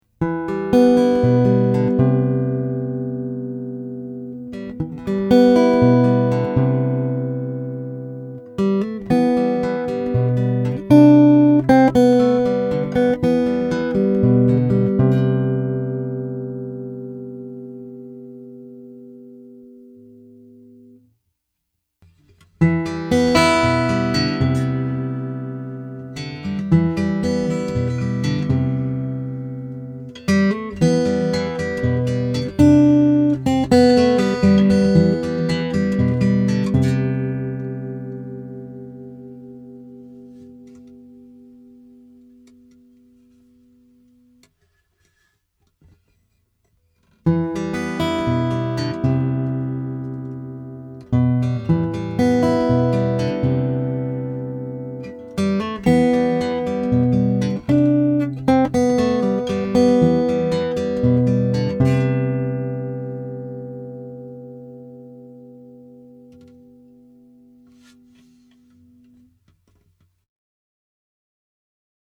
The first three were recorded completely dry. No EQ, no compression. I play the same riff three times in each clip, varying the amount of condenser mic in each. The first part isolates the magnetic pickup with no condenser, the second part has the condenser opened up wide. The third part has the condenser mic set to about 50%.
Fingerstyle
fingerstyle.mp3